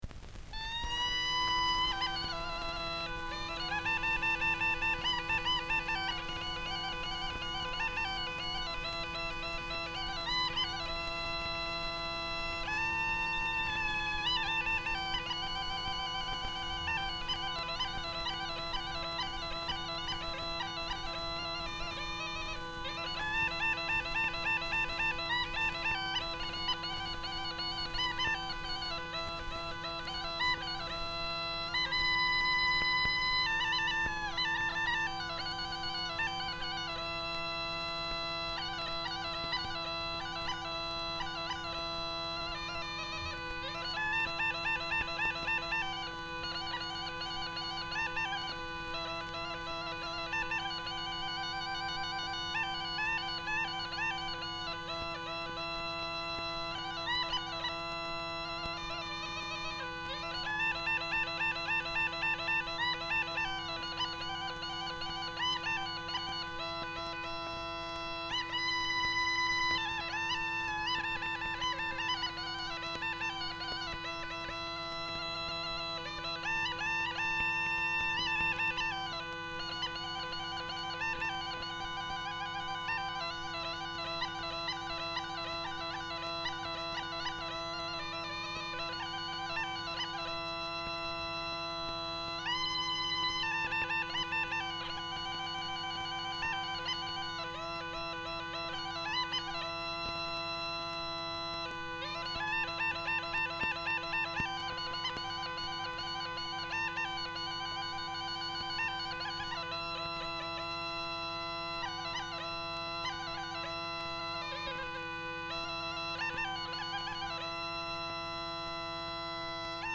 Piese de muzica populara, instrumentala